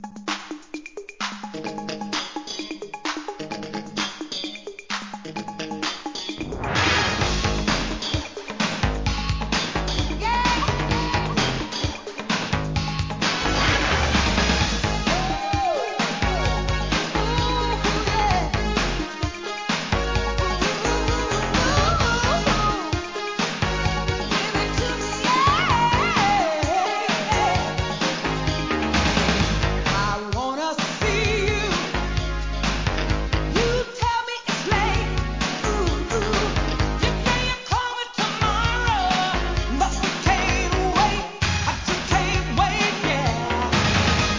SOUL/FUNK/etc...
ノリノリダンスチューン!